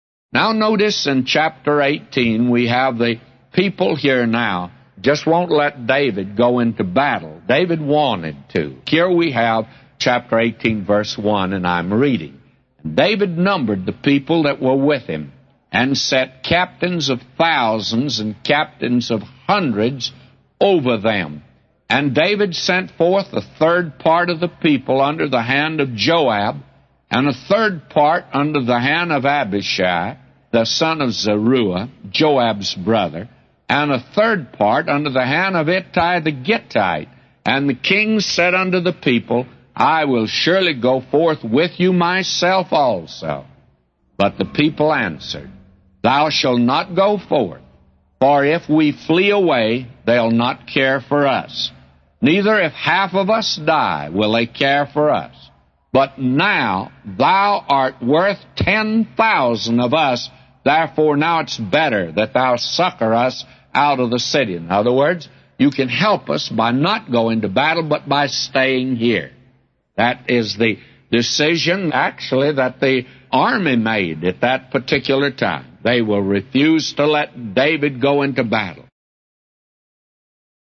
A Commentary By J Vernon MCgee For 2 Samuel 18:1-999